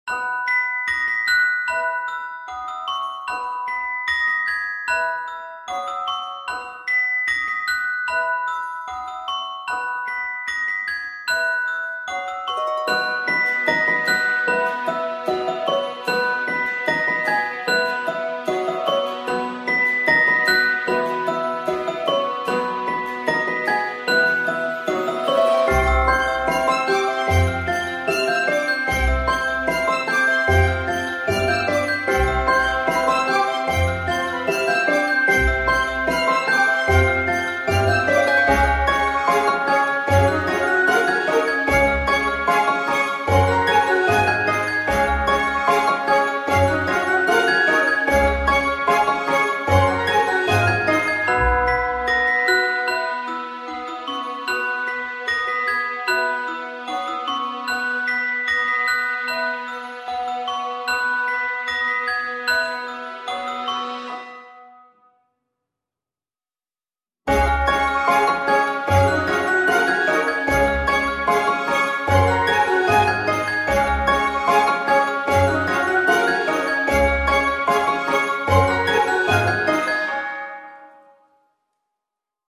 Звук и мелодия рождественской сцены